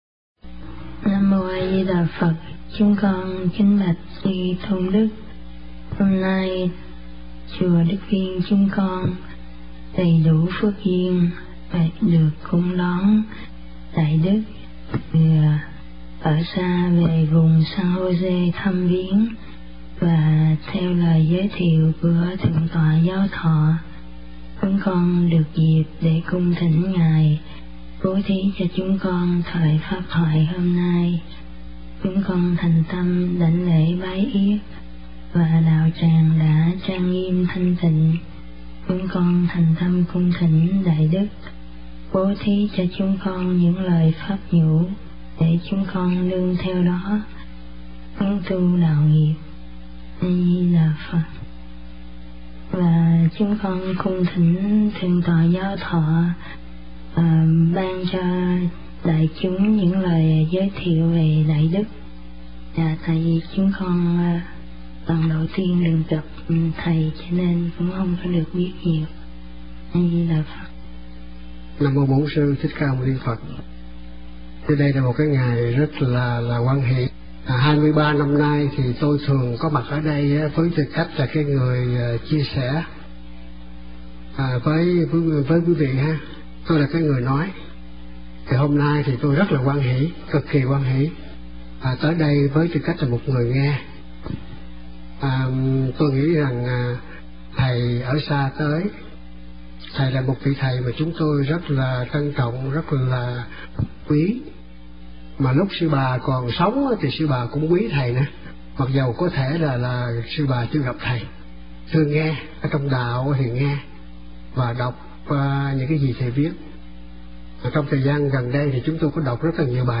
Mp3 Thuyết Pháp Tịnh độ nhân gian
Giảng tại Chùa Đức Viên, San Jose, ngày 10 tháng 10 năm 2004